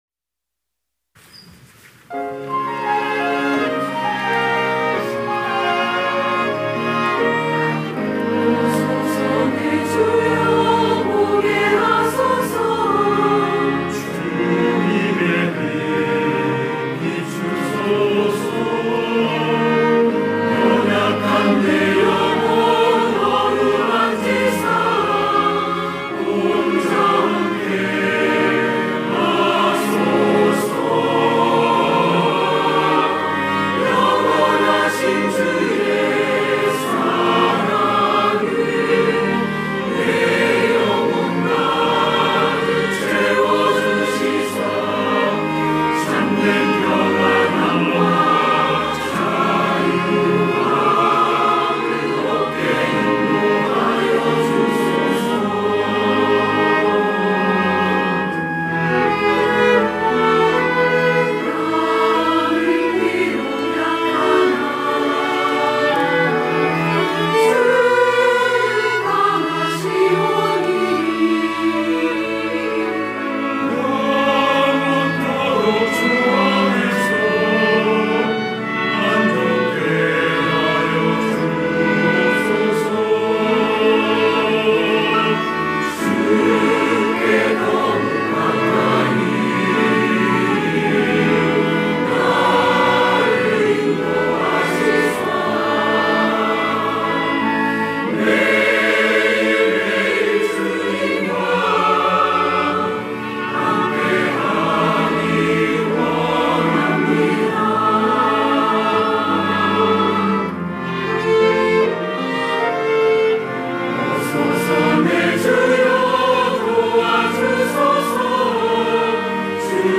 호산나(주일3부) - 오소서, 내 주여
찬양대